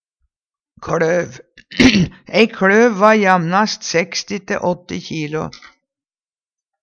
kLøv - Numedalsmål (en-US)